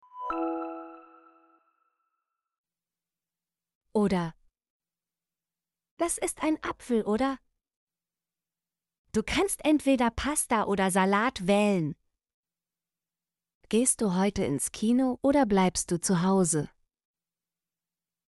oder - Example Sentences & Pronunciation, German Frequency List